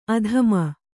♪ adhama